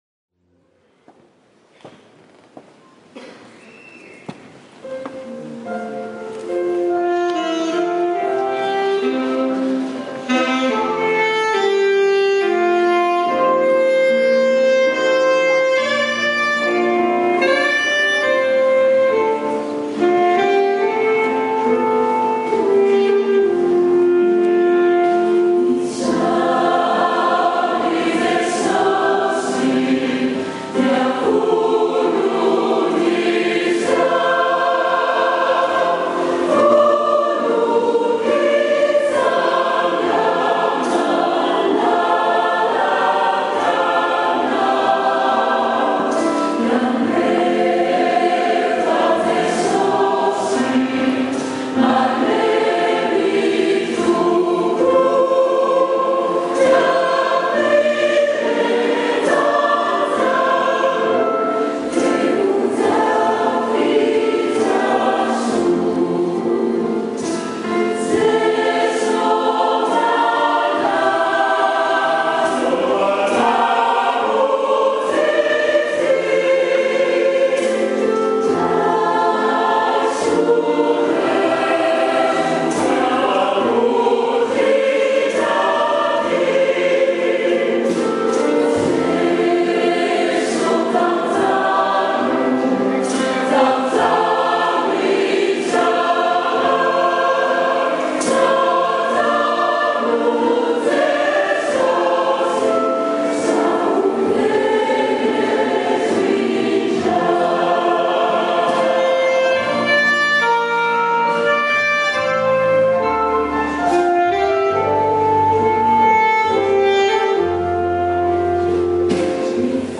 Izaho_ry_Jesosy_-_Chorale_Fiderana_-_FPMA_Paris.mp3